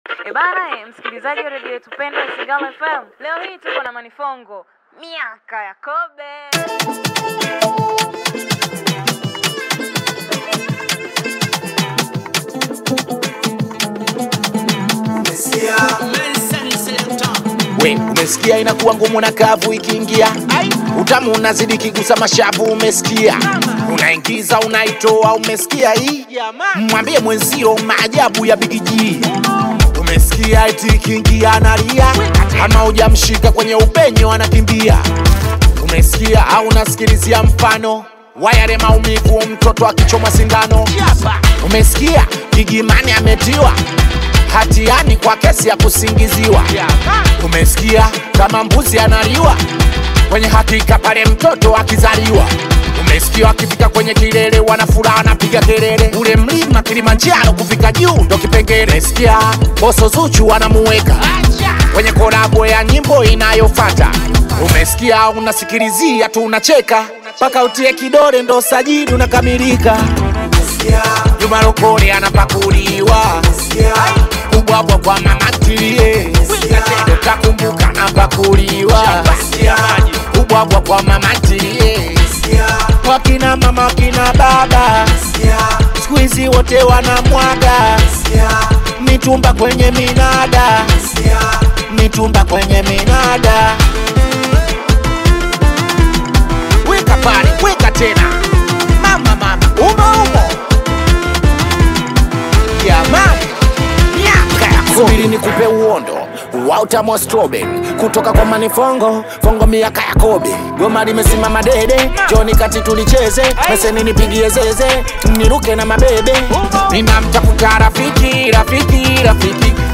Singeli track